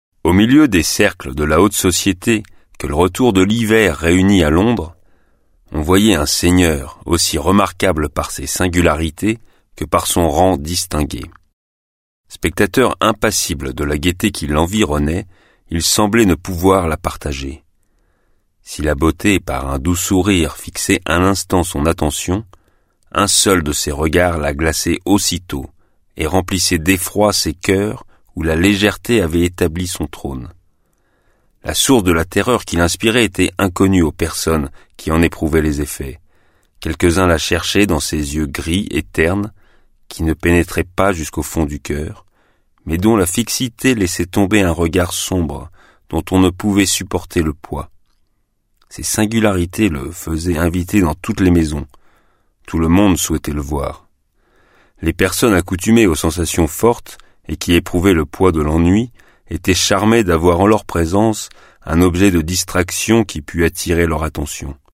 Click for an excerpt - Le Vampire de John Polidori